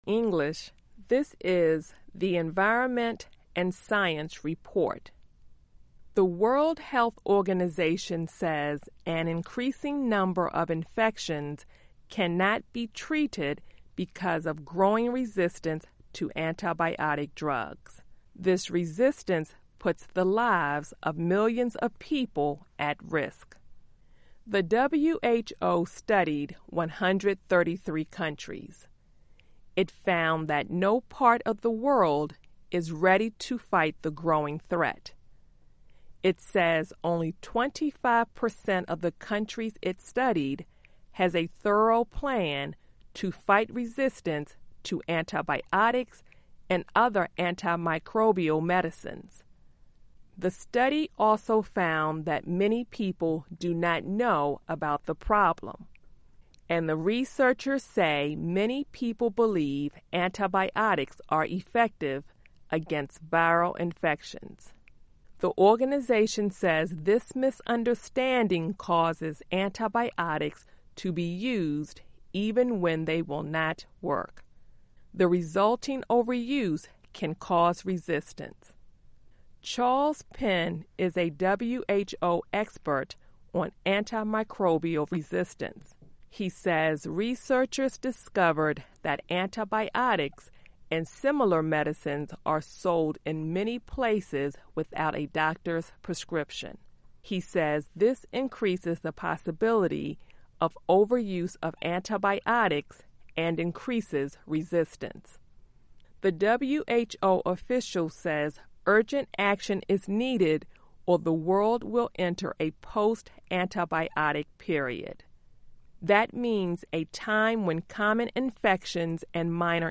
Learning English as you listen to a weekly show about the environment, science, farming, food security, gardening and other subjects. Our daily stories are written at the intermediate and upper-beginner level and are read one-third slower than regular VOA English.